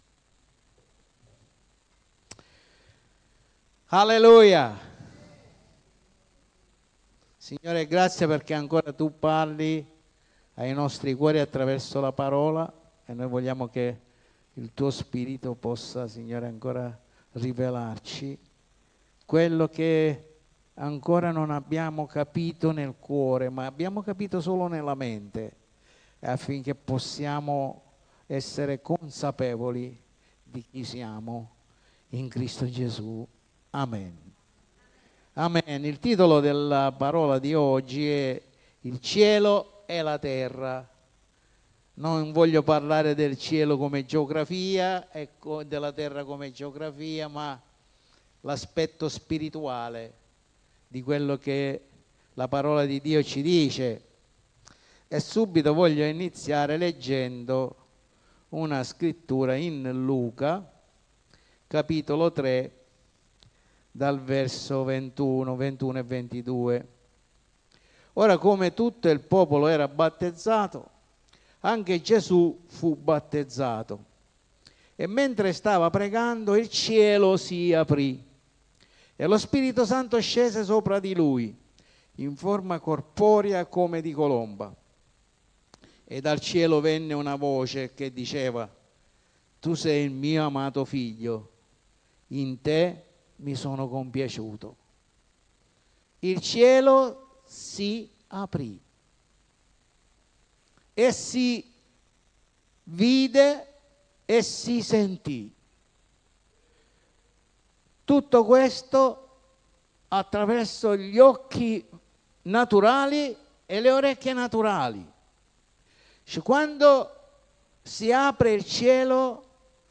PREDICAZIONI
Sezione del sito per l'ascolto dei messaggi predicati la domenica e per il riascolto di studi biblici